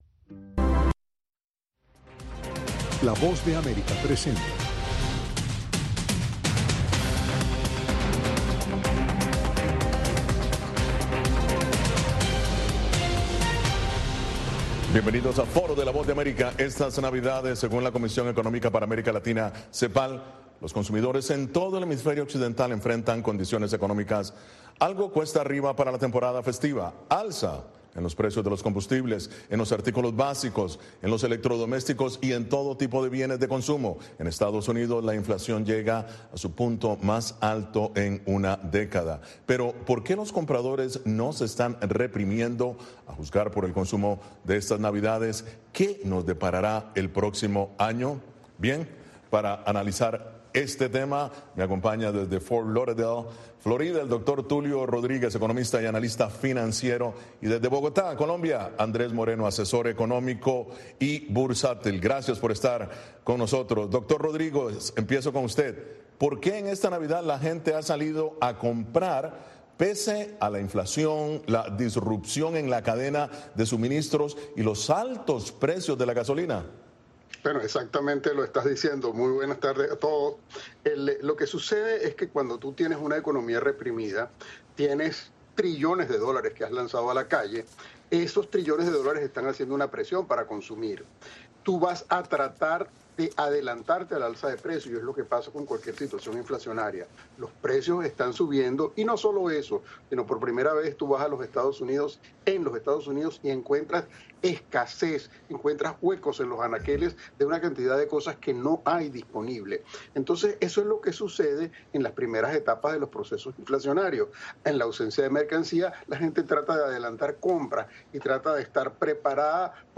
El punto de encuentro para analizar y debatir, junto a expertos, los temas de la semana.